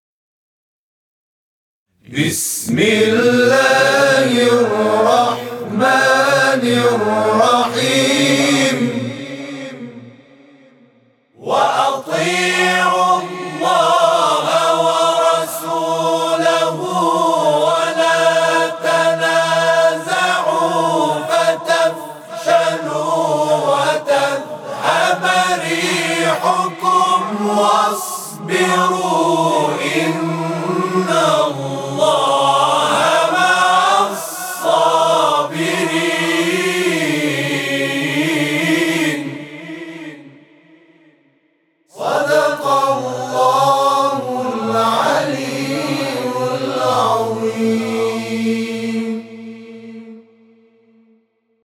صوت همخوانی آیه 46 سوره انفال از سوی گروه تواشیح «محمد رسول‌الله(ص)»